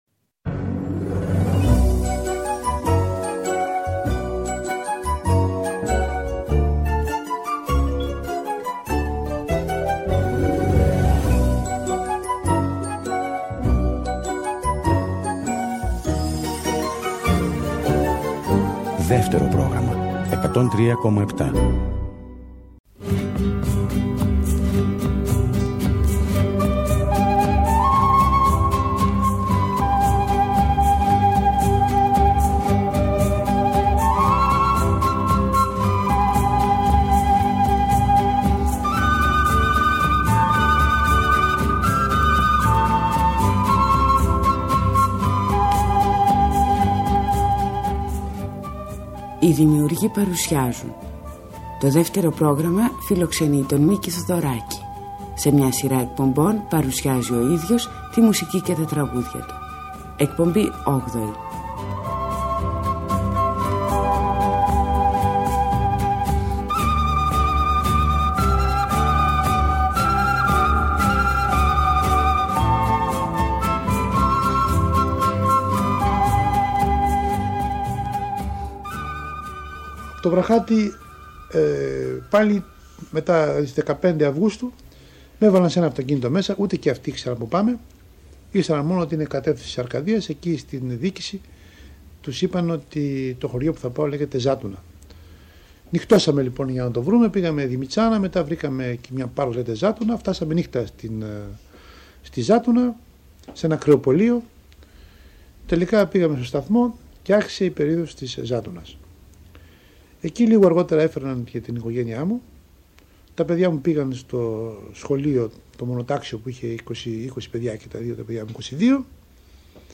τον Μίκη Θεοδωράκη, μόνο μπροστά στο μικρόφωνο να ξετυλίγει με τον δικό του τρόπο το κουβάρι των αναμνήσεών του και να αφηγείται τη ζωή του.